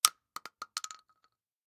Bullet Shell Sounds
shotgun_generic_4.ogg